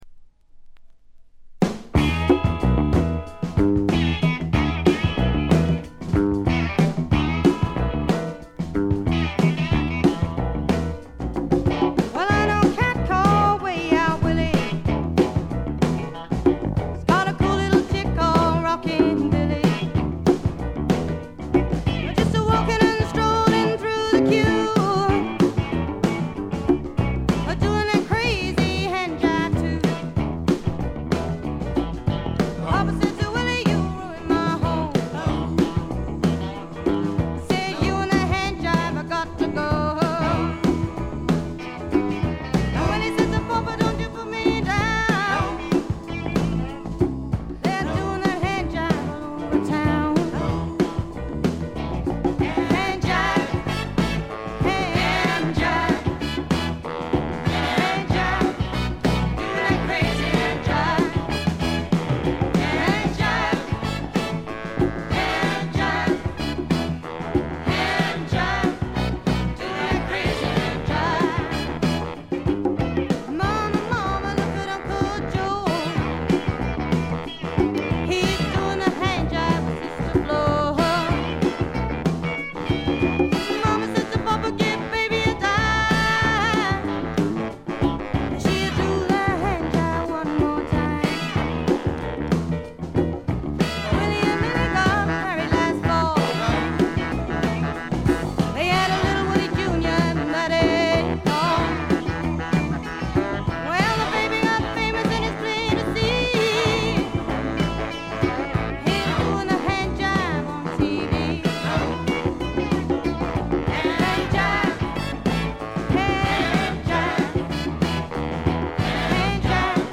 部分試聴ですが軽微なチリプチ少し。
試聴曲は現品からの取り込み音源です。